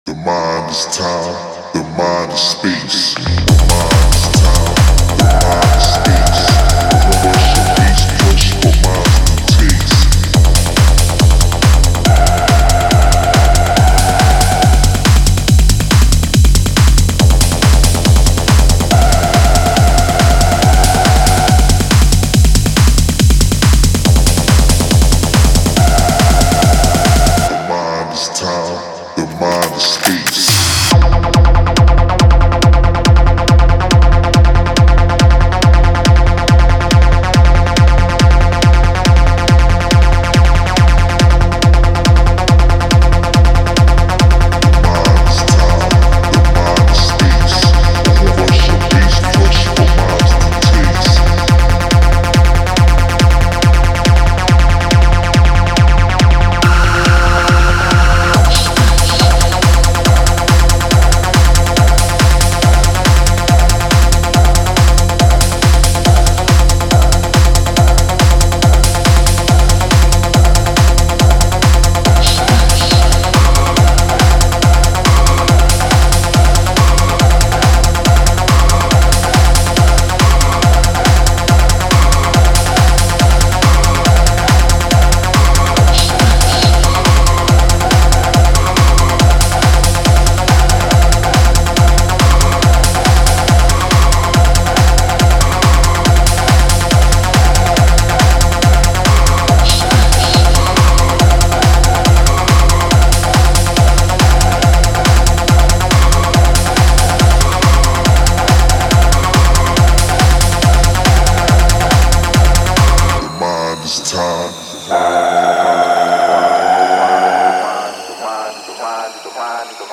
Techno Contest - Acid/Rave Edition